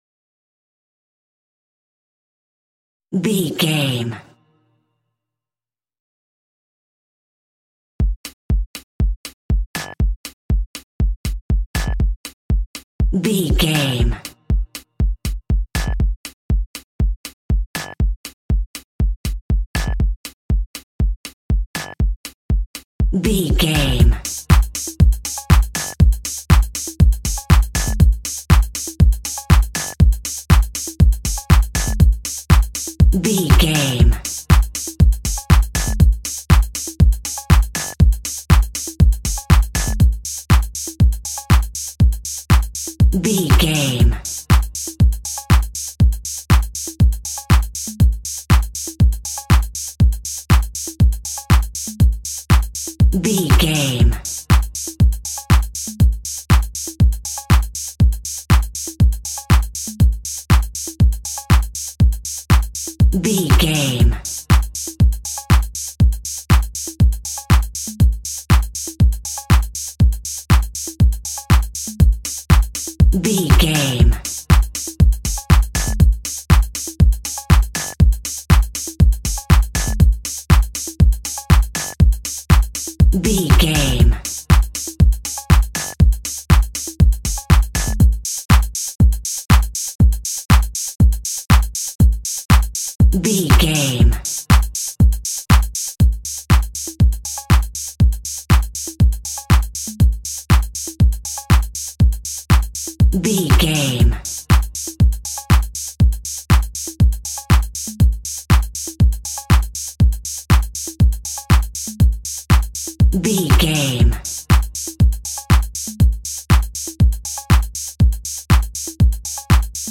Aeolian/Minor
groovy
futuristic
uplifting
electric guitar
bass guitar
funky house
electronic funk
energetic
upbeat
synth leads
Synth Pads
synth bass
drum machines